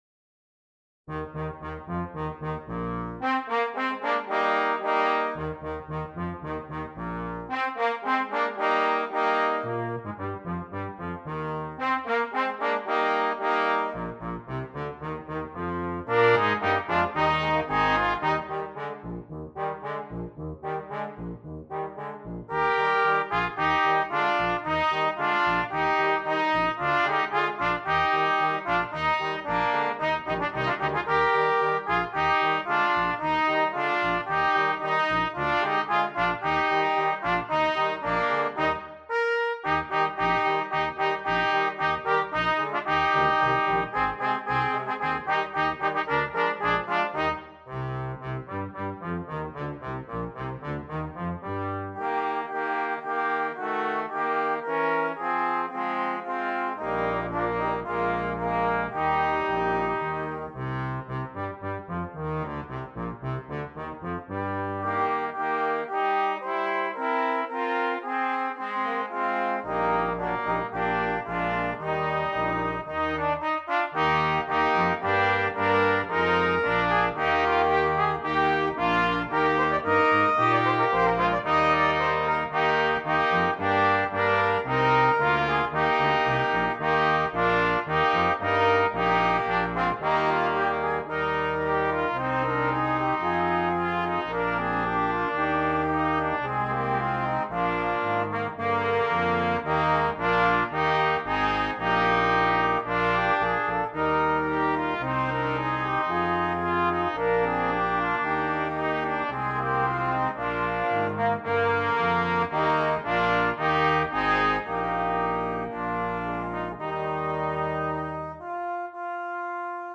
Christmas
Brass Quintet